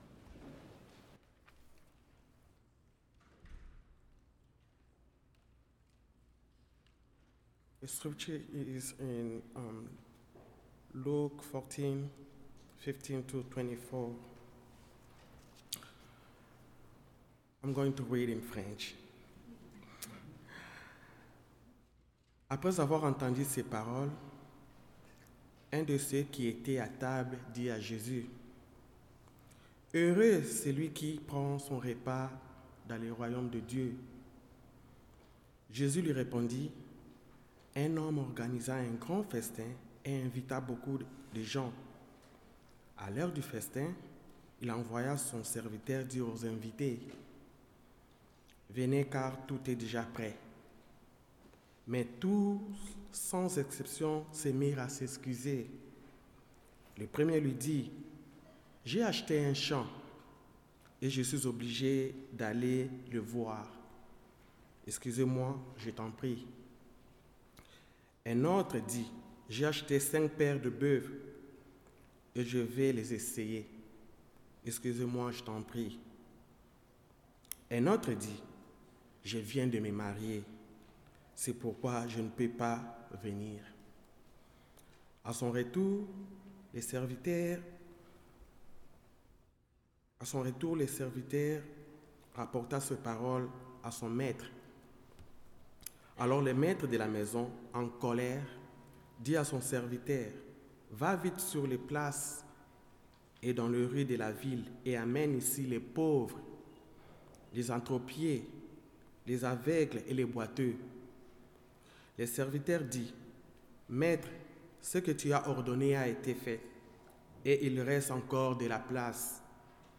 Passage: Philippians 2:1-11 Service Type: Sunday Worship